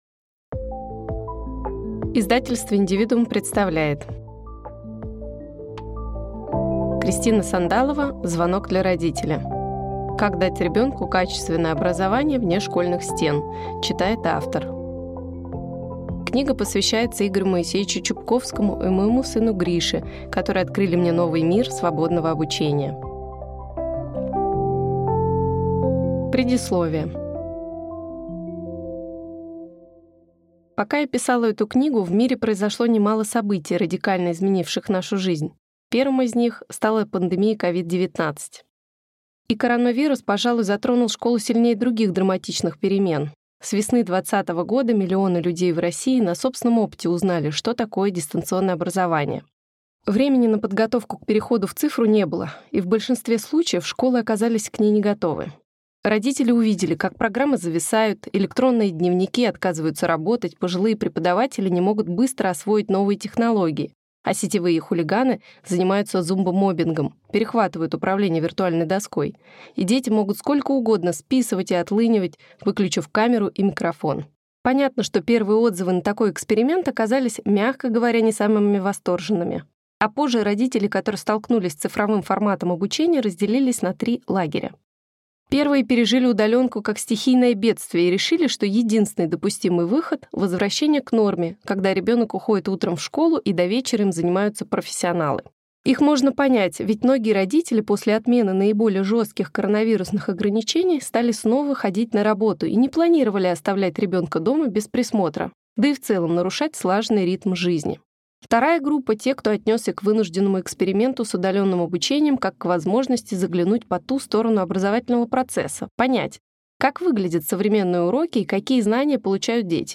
Аудиокнига Звонок для родителя. Как дать ребенку качественное образование вне школьных стен | Библиотека аудиокниг